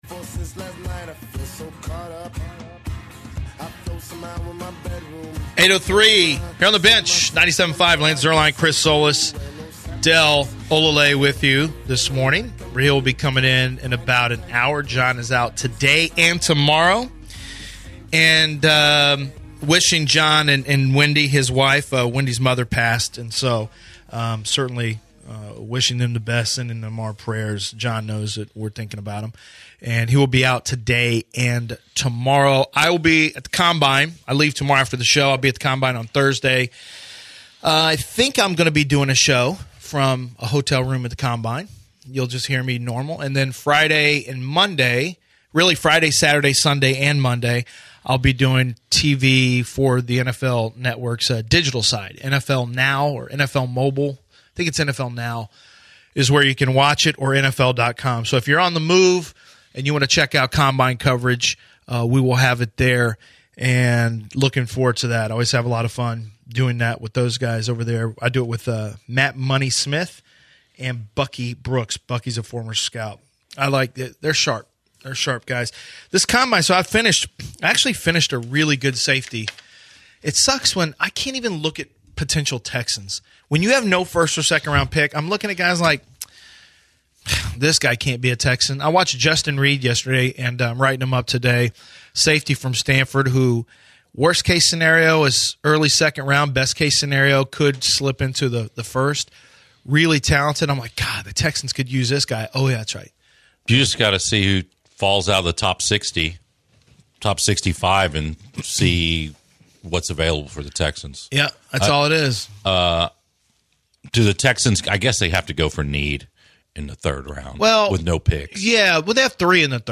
In the second hour, the guys started with Texan’s drafting opportunities. They then discussed comparison Rocket’s vs Warrior’s big 4 players. The guys go into CH 26 TV studio for the rest of the show.